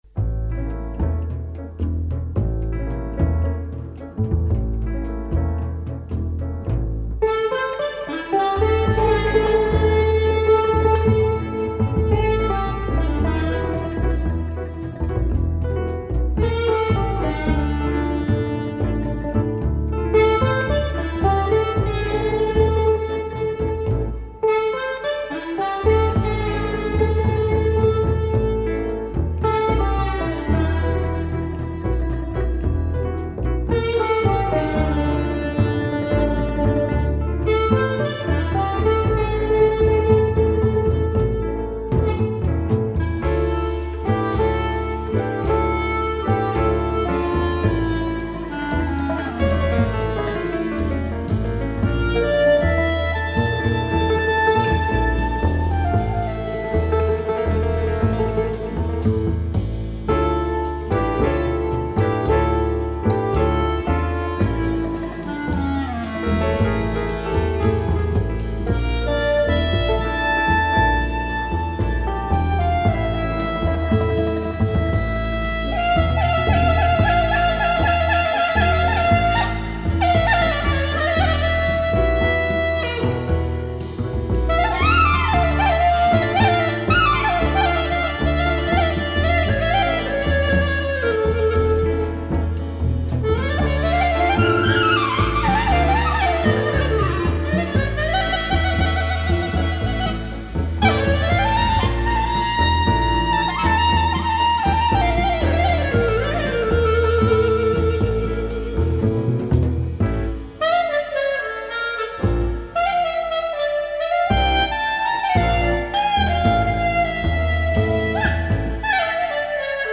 Amazing Greek jazz album